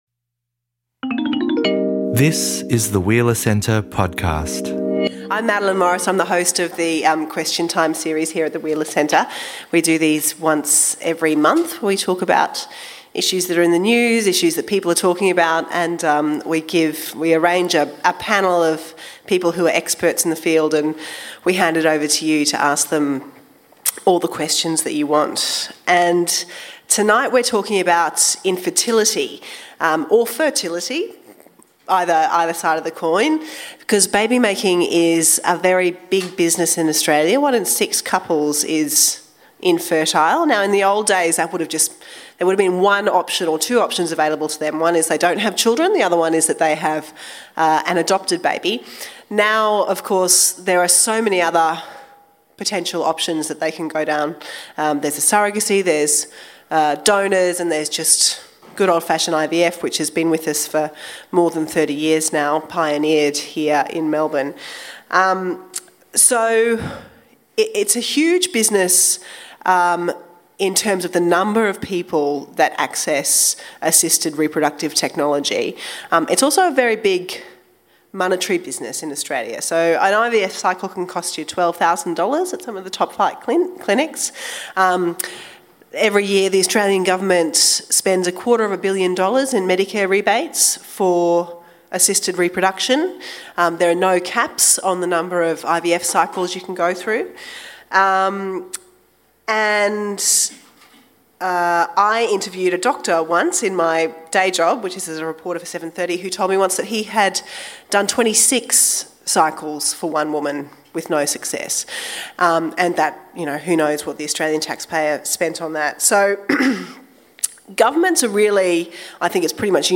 The growing industry around assisted reproduction is largely privatised and third-party reproduction avenues raise many ethical questions, from gender selection to the commodification of children to the rights of donor-conceived children and commercial surrogate mothers. At this Question Time discussion, we delve into the personal, ethical and legal implications of infertility and its treatment.